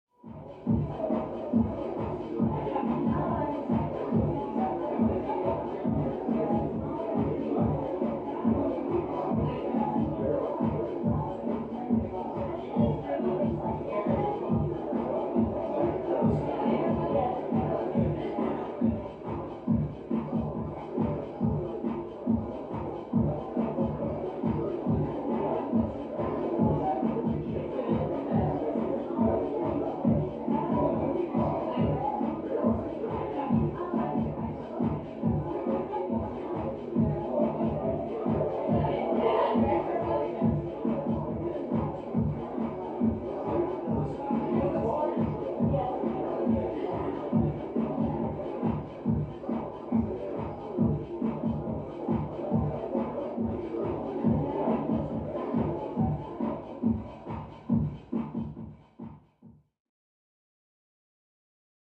Звуки вечеринки, парада
Шум вечеринки с громкой музыкой